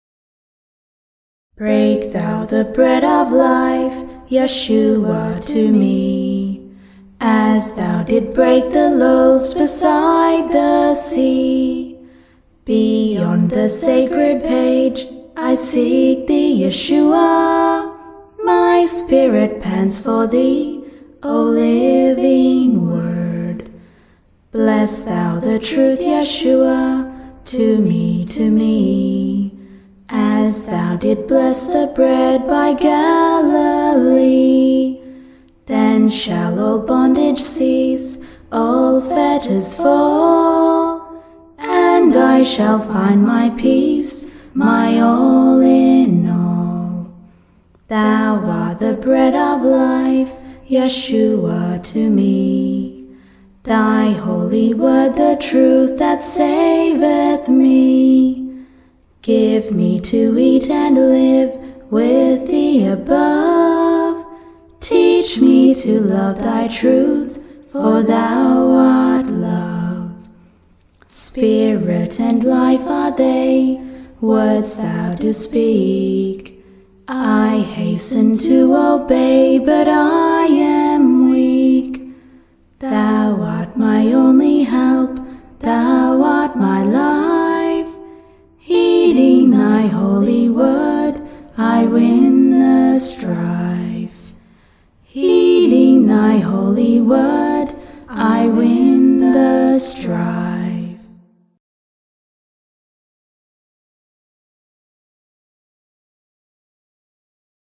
Click Here to Singalong with Helidon MMM Choir: